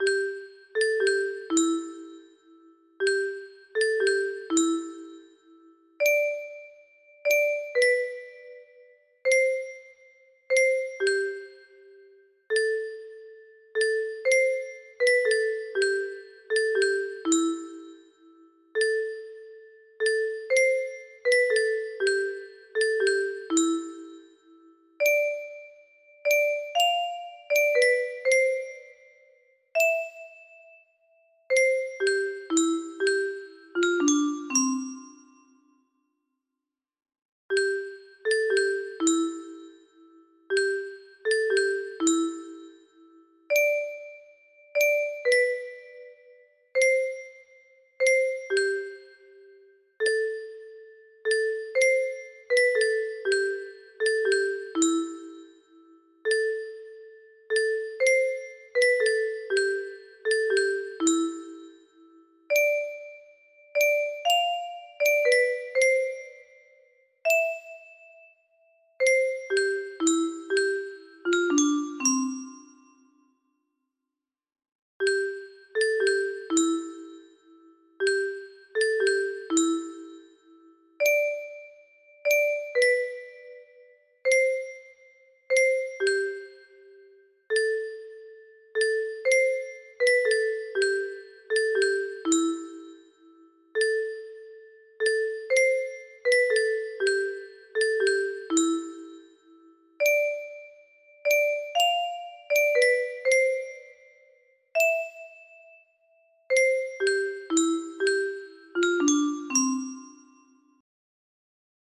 Mohr and Gruber - Silent night - Soprano music box melody